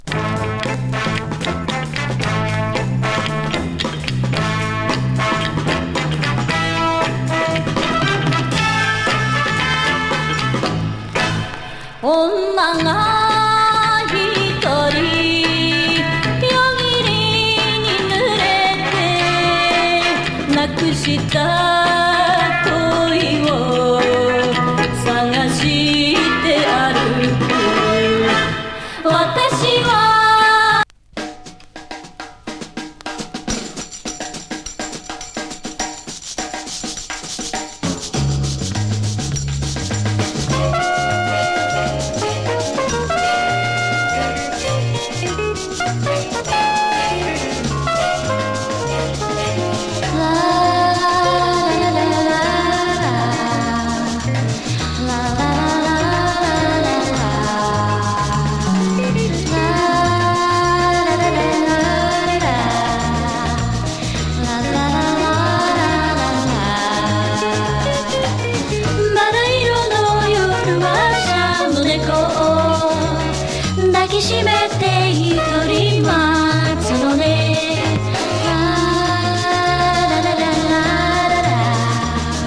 ズンドコ昭和歌謡
風呂場風エコー・スキャット・グルーヴィ
盤チリノイズ入ります